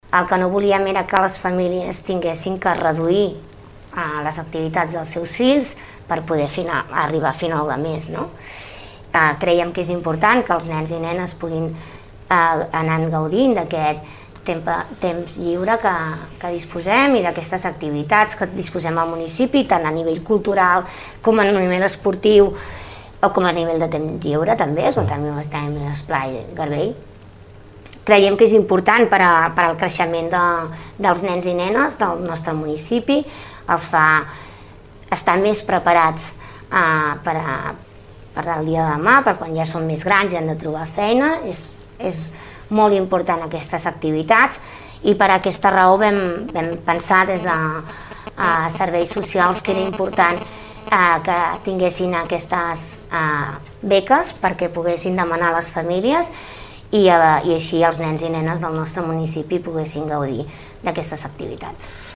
Escoltem la regidora Toñi Garcia.